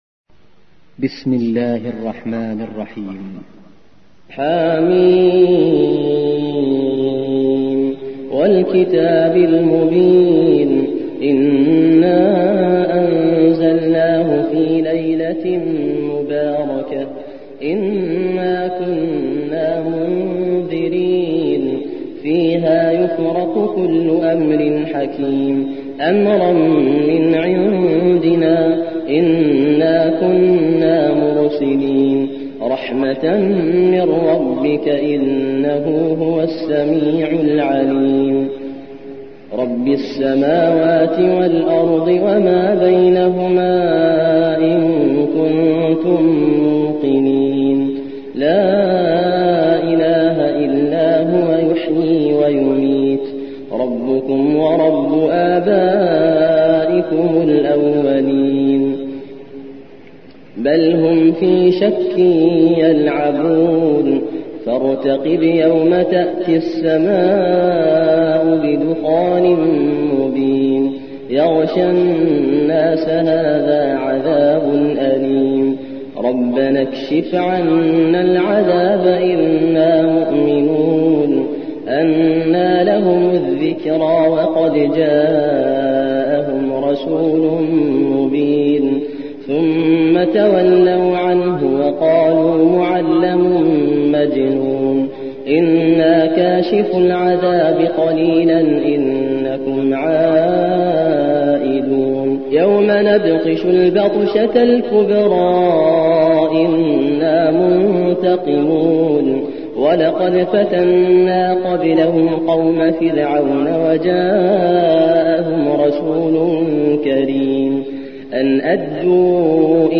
44. سورة الدخان / القارئ